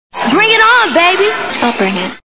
Bring it On Movie Sound Bites